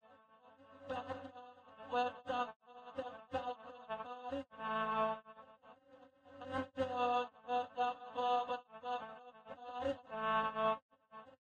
It reproduced a good pitch extraction but lost the words.
extraction using M = 3